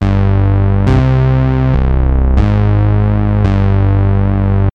Ces derniers sont produits par des signaux électriques.
Synthétiseur